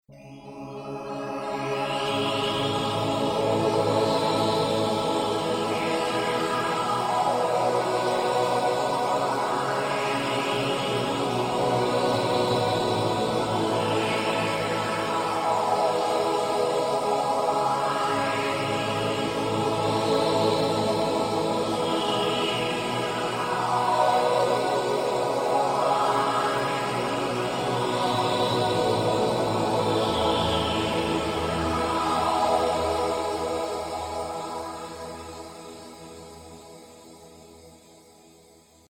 simple non-resonant digital lowpass filter (VDF) with no special control.
HEAR 03rw lowpass filter